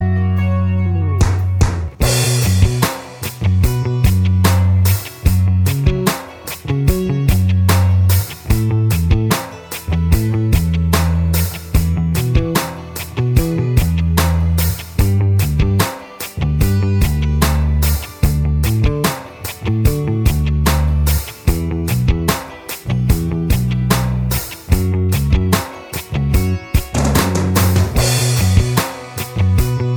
Minus Guitar Solos Soft Rock 6:34 Buy £1.50